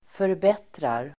Uttal: [förb'et:rar]